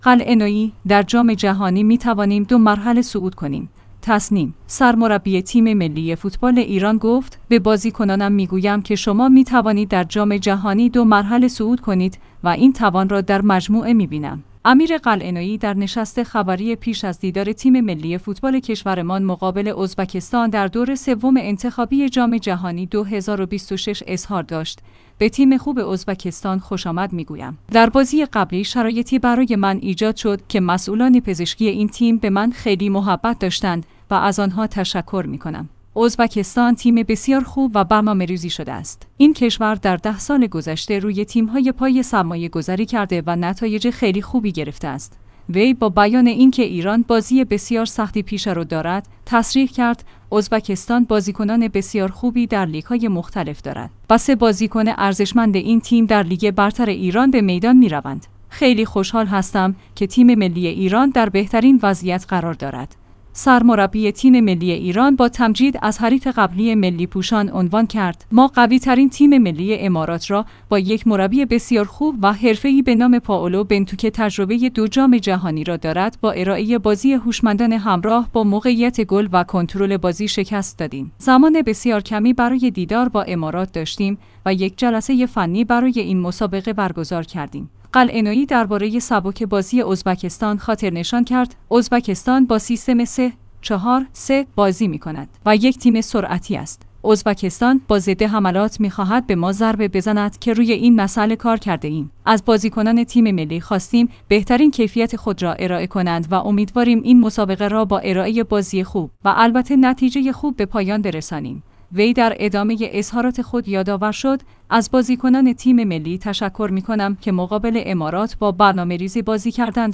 امیر قلعه‌نویی در نشست خبری پیش از دیدار تیم ملی فوتبال کشورمان مقابل ازبکستان در دور سوم انتخابی جام جهانی 2026 اظهار داشت: به تیم خوب ازبکستان خوشامد می‌گو